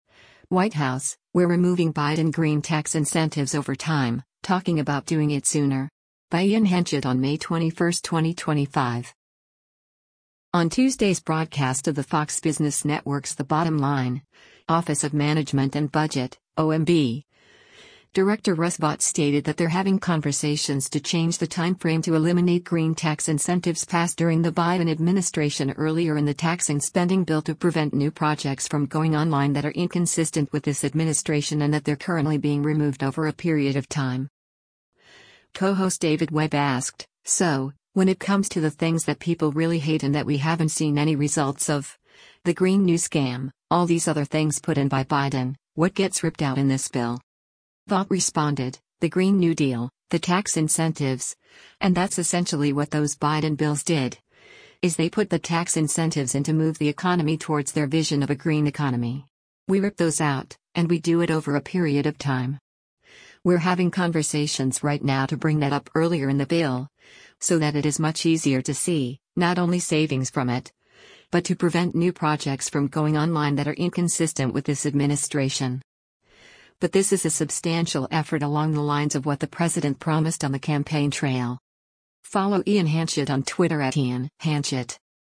On Tuesday’s broadcast of the Fox Business Network’s “The Bottom Line,” Office of Management and Budget (OMB) Director Russ Vought stated that they’re having conversations to change the timeframe to eliminate green tax incentives passed during the Biden administration earlier in the tax and spending bill “to prevent new projects from going online that are inconsistent with this administration” and that they’re currently being removed “over a period of time.”